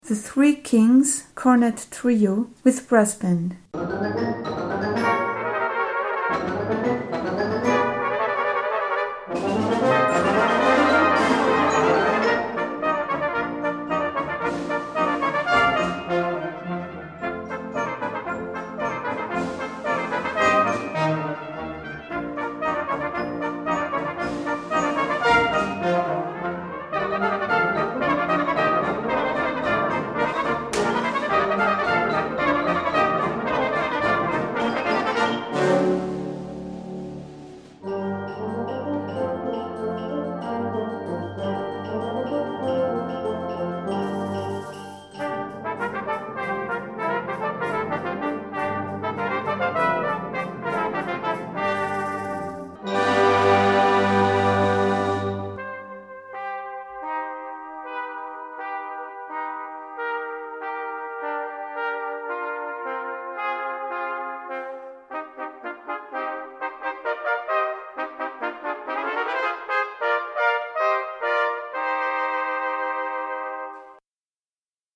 Brass Band
Trio & Brass Band